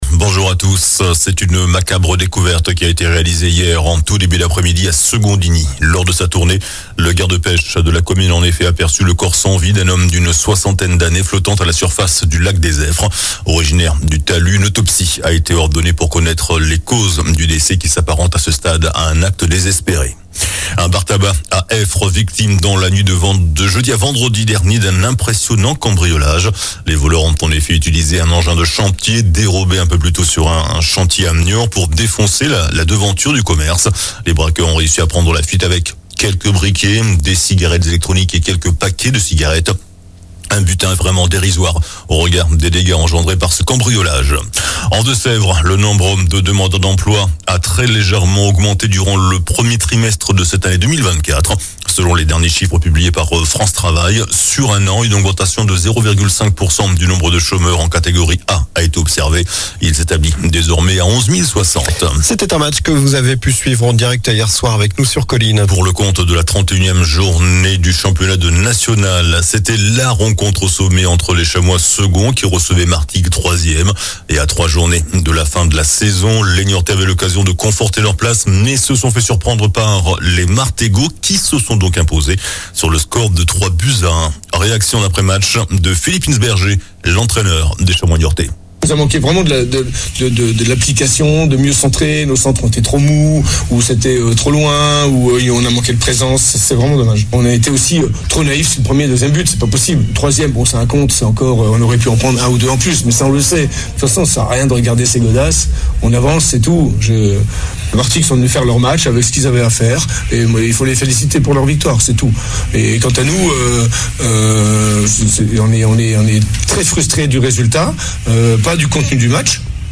JOURNAL DU SAMEDI 27 AVRIL